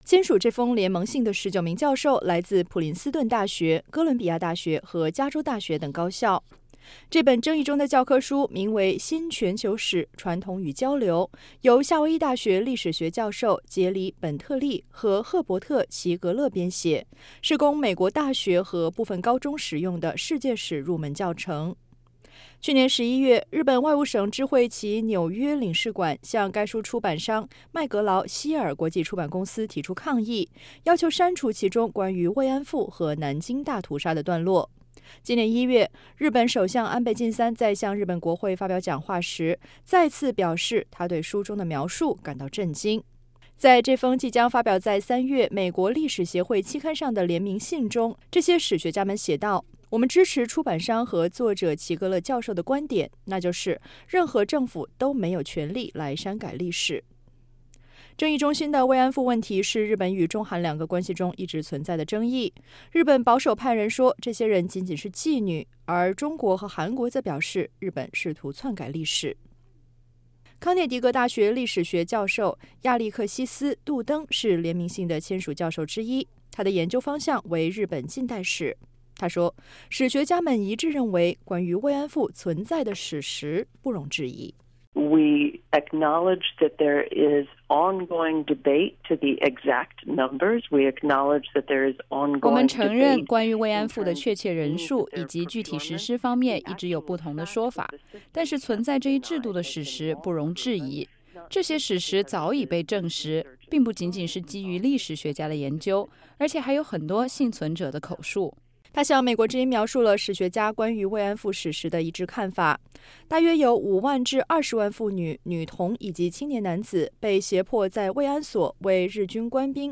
华盛顿报道